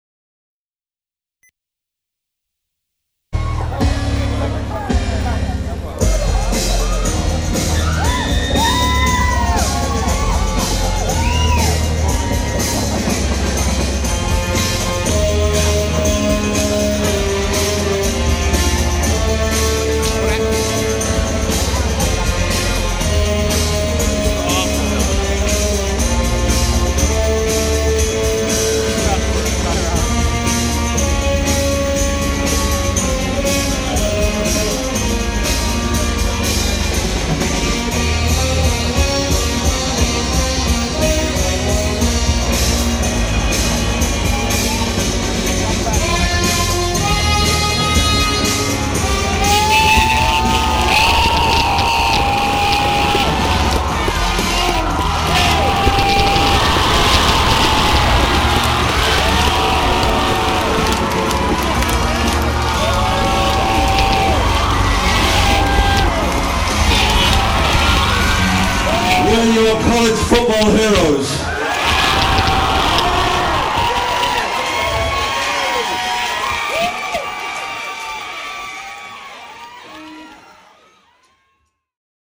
ものすごい狂乱だった。